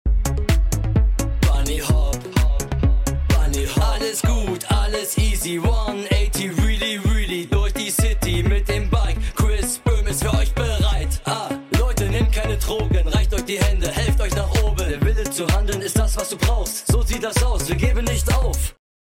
mit dieser coolen Hook
Auch großes Danke schön geht an diesen Kirchenchor